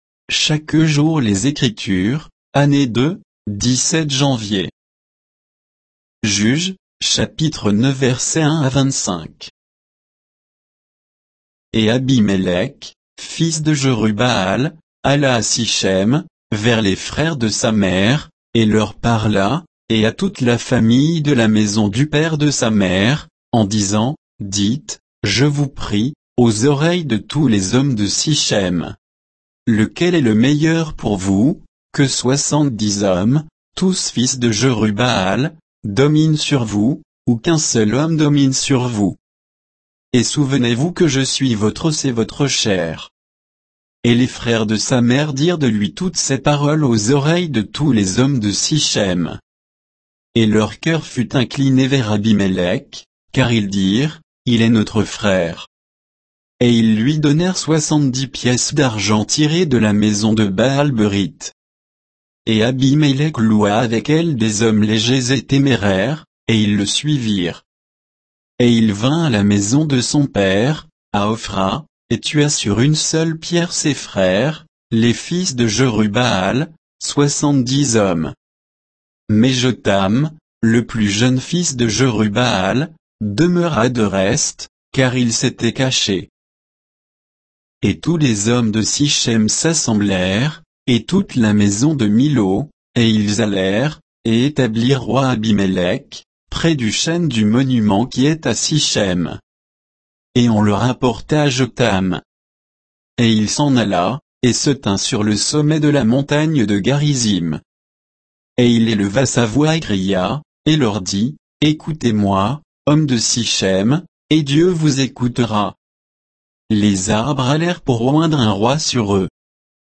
Méditation quoditienne de Chaque jour les Écritures sur Juges 9